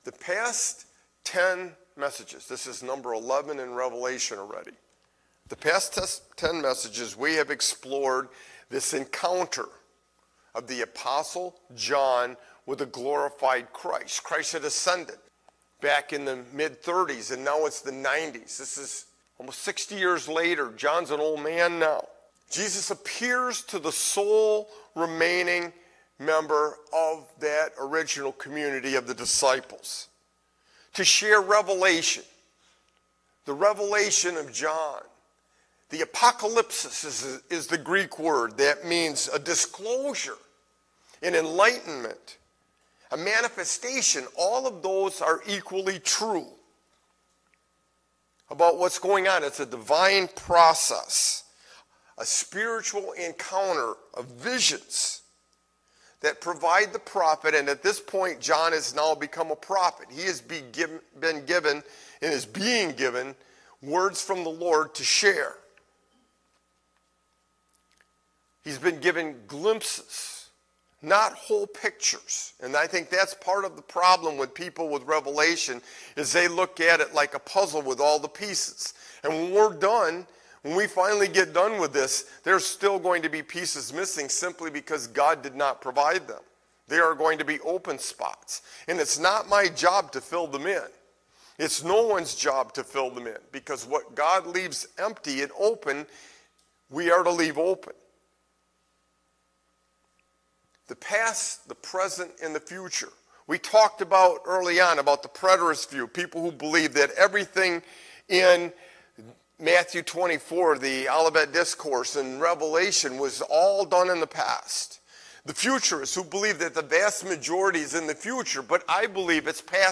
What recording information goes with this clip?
County-Line-Community-Church-Live-Stream.mp3